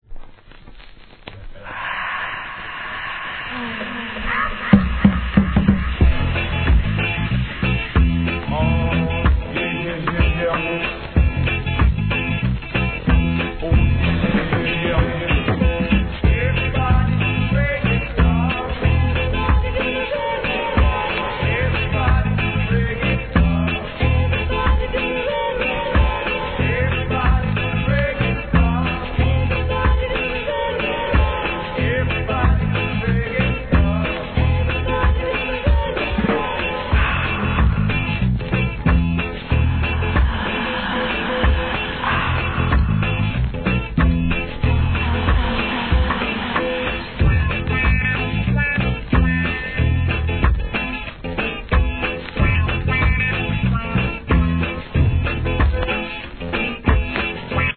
のレゲエ・カヴァー